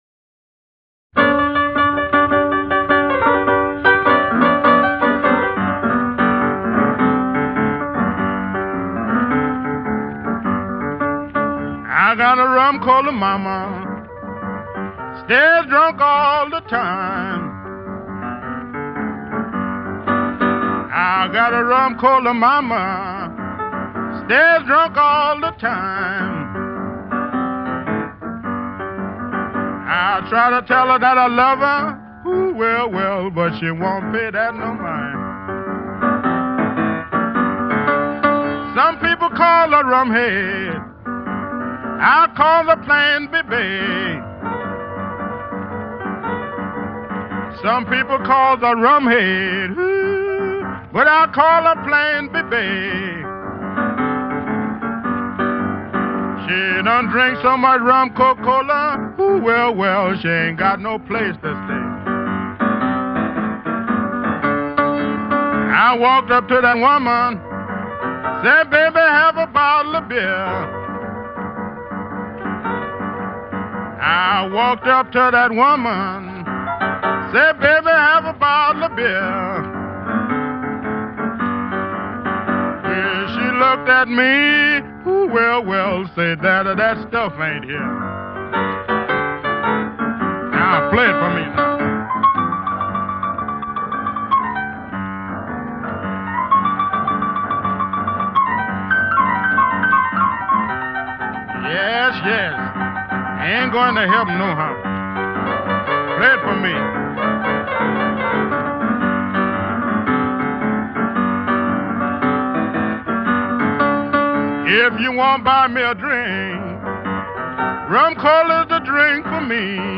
What would be the champ pianoplaying, apart from me?